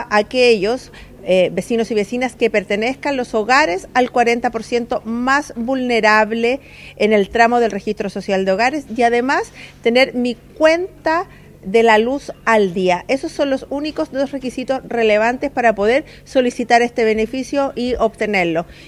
La seremi de Energía, Claudia Lopetegui, detalló los principales requisitos para el segundo llamado.
cu-subsidio-1-seremi.mp3